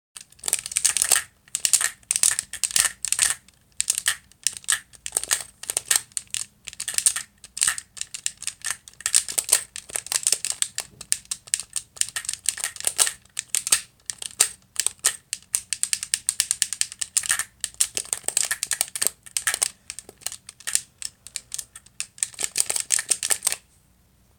Field Recording
The sound a gamecube controller makes when the buttons are aggressively pushed.
GameCube-Controller.mp3